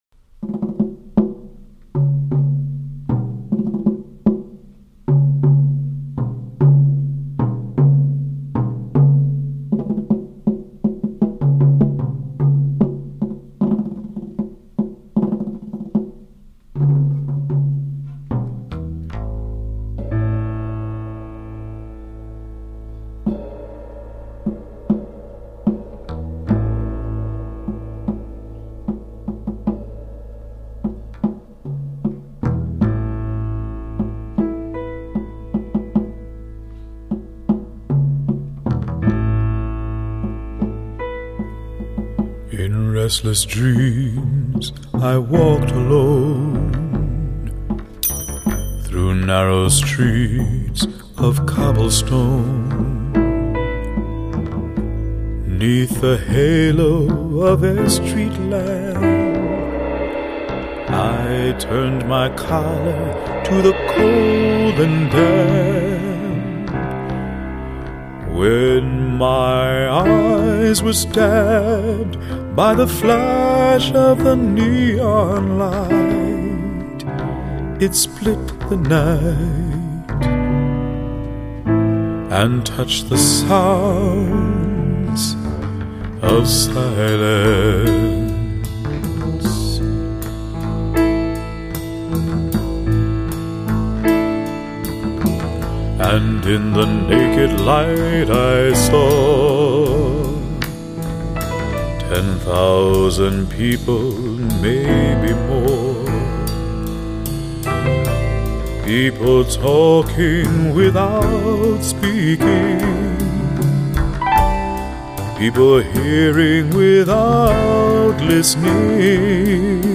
所以整张专辑带有真空管录音的那种独特气味--人声甜美而丰厚，质感非常非常好。
在本片黑人歌手充满磁性的歌声中，一定会令新婚的夫妇感觉更加轻松，更加助兴的。